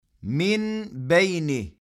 Tenvin veya Cezimli Nun’dan sonra Be ( ب ) harfi gelirse Nun ( ن ) sesi tamamen Mim ( م ) sesine dönüştürülür. Mim sesi genizde akıtıldıktan sonra ses kesilmeden Be harfi ile okumaya devam edilir.
Türkçede “On Beş” için “OmmBeş” okunması gibidir.